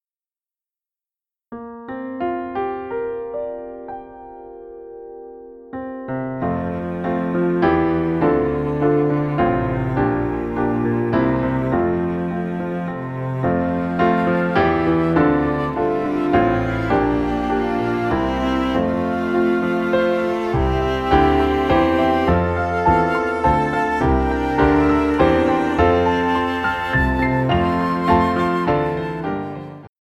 Entdecken - Erzählen - Begreifen: Spiellieder mit CD.